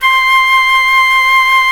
Index of /90_sSampleCDs/Roland LCDP04 Orchestral Winds/CMB_Wind Sects 1/CMB_Wind Sect 3